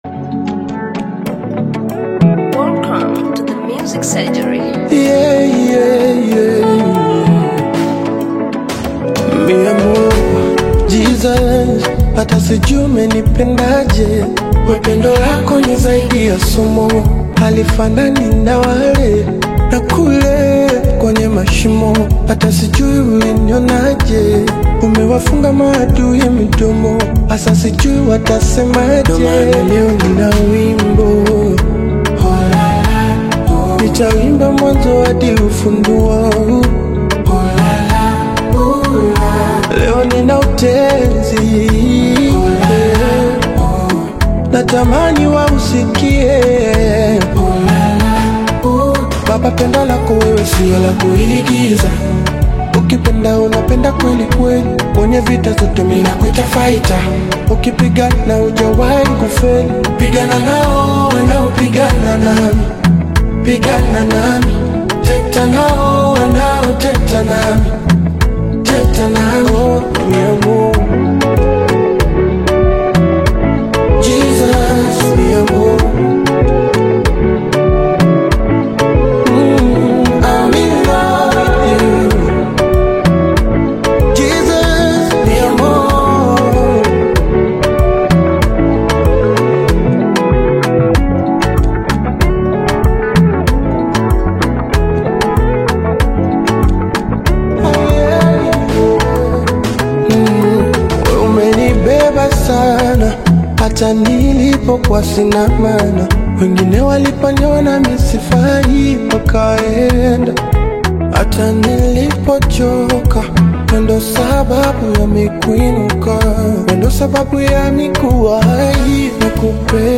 AudioGospel
Genre: Gospel